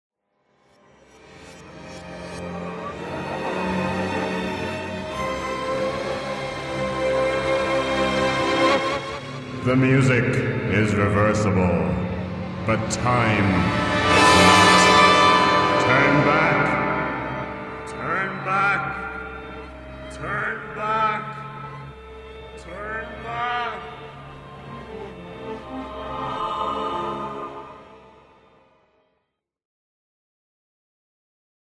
rock sinfonico
Tipo di backmasking Rovesciato